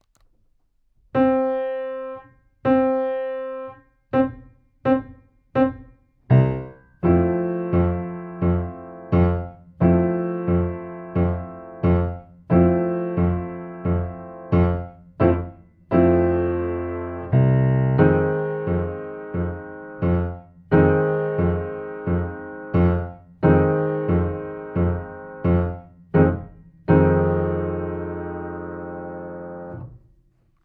カラオケ音源1inC